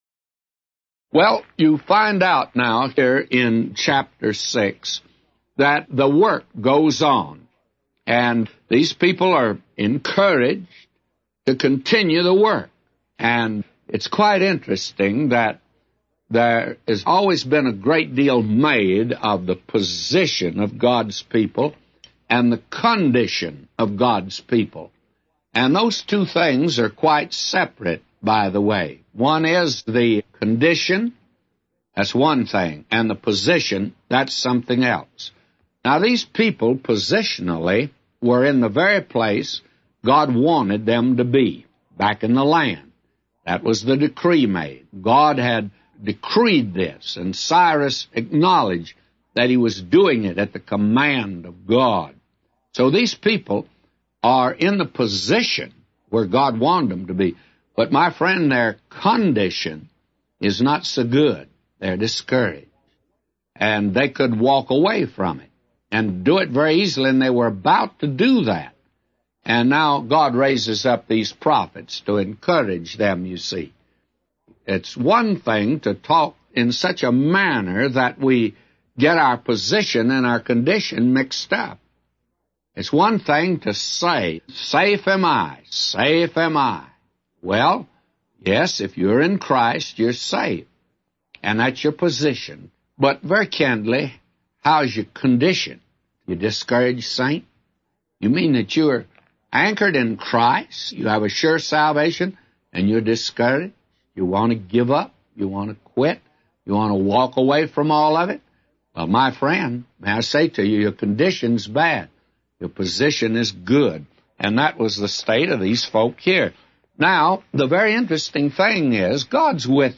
A Commentary By J Vernon MCgee For Ezra 6:1-999